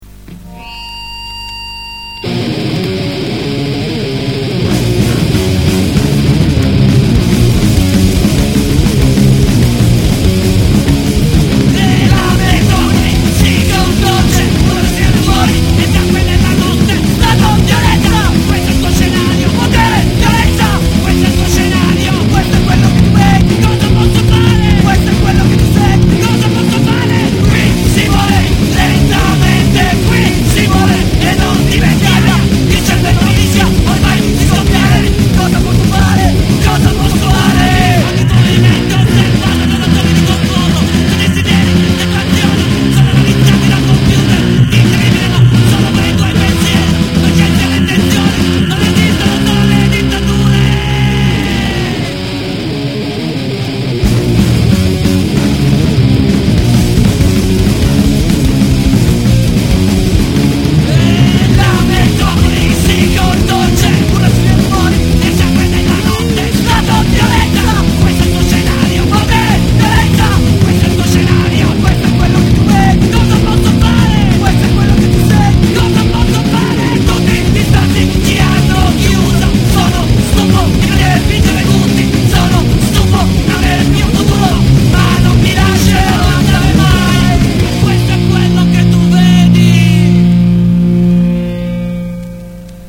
(1997, Hardcore, Torino)